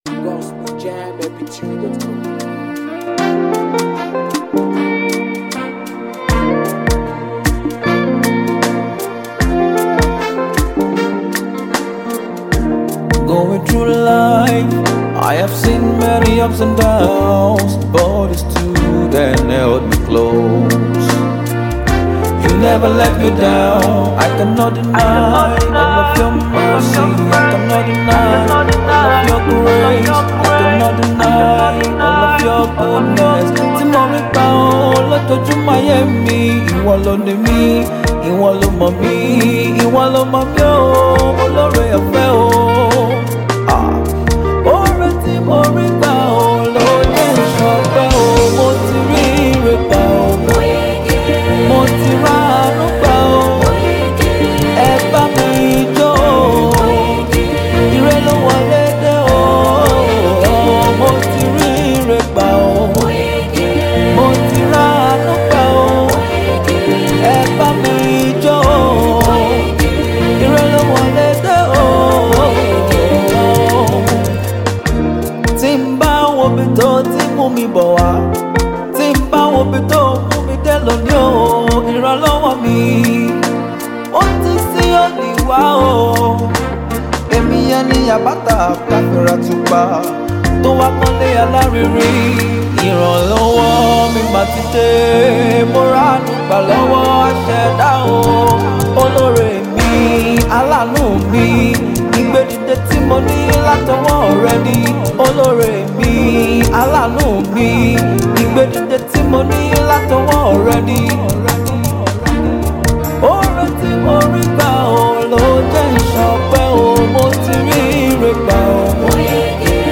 African praisemusic